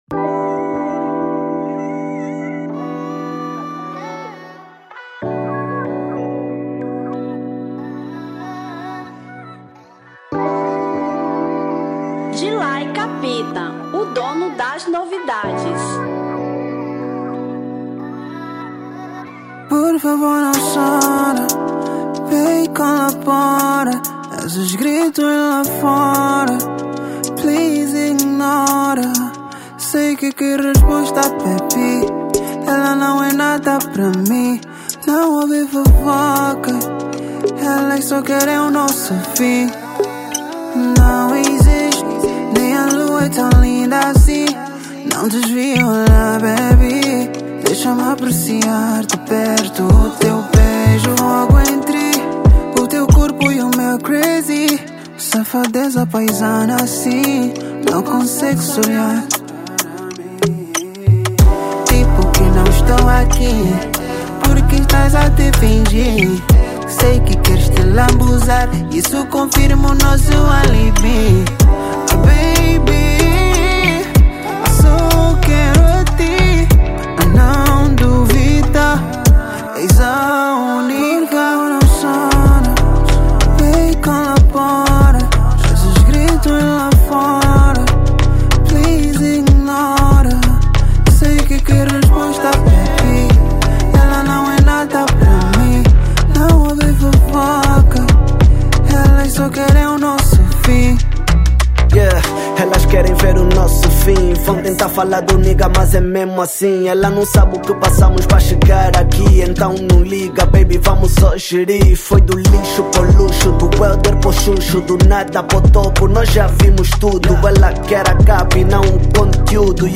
Afro Pop 2025